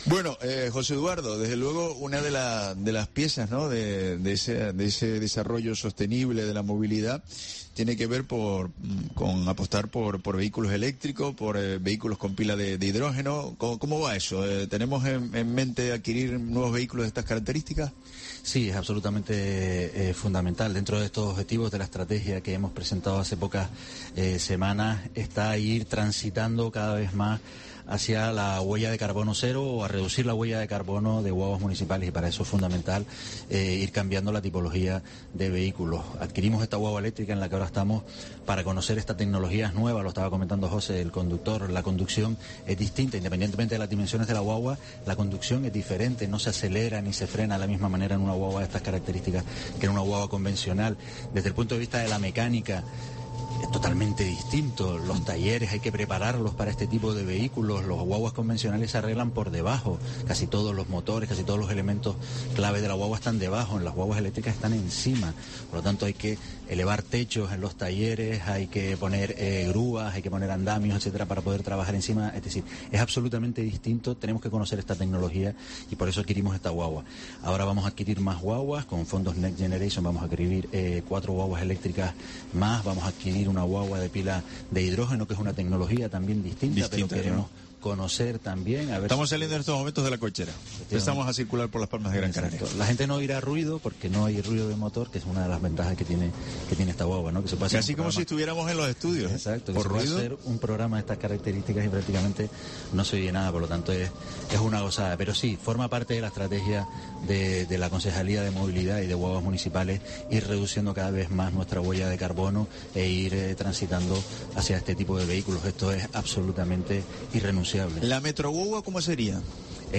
Entrevista José Eduardo Ramírez, concejal de Movilidad del Ayuntamiento de Las Palmas de Gran Canaria